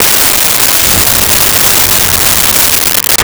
Car Start 02
Car Start 02.wav